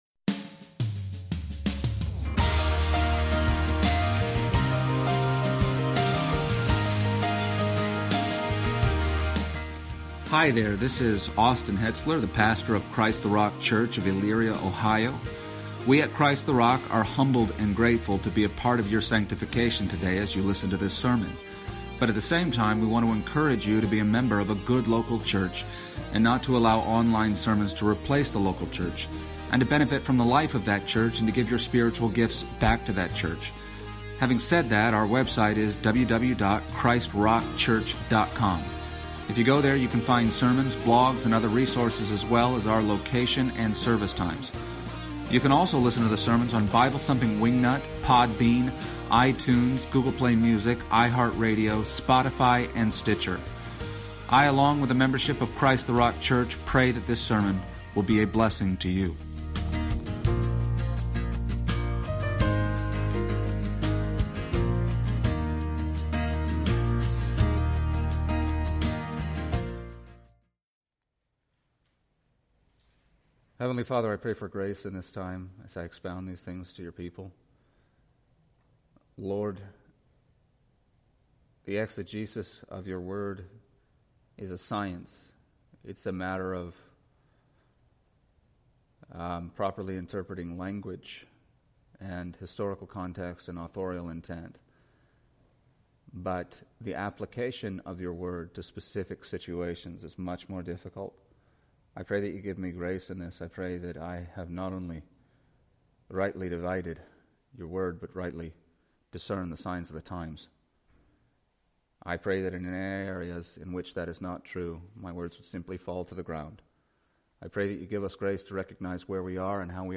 Passage: Romans 1:18-32 Service Type: Sunday Morning